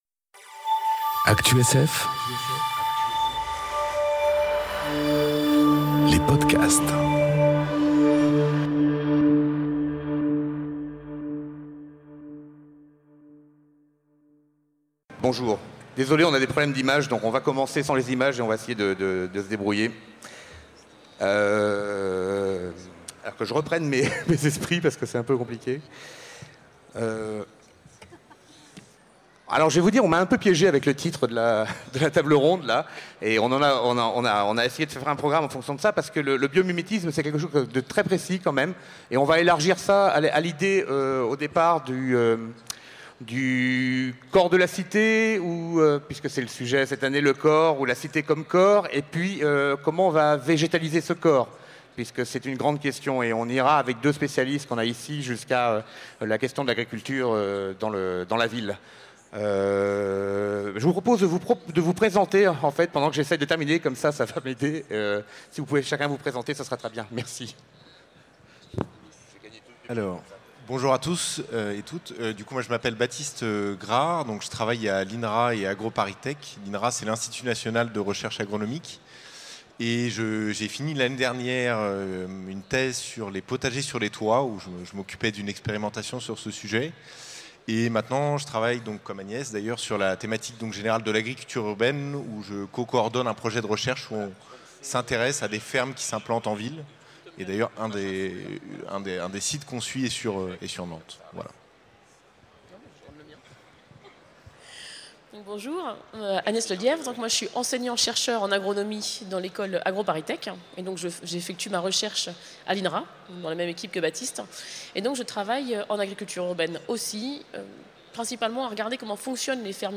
Conférence Les cités biomimétiques ou les nouveaux jardins de Babylone enregistrée aux Utopiales 2018